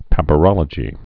(păpə-rŏlə-jē)